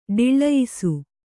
♪ ḍiḷḷayisu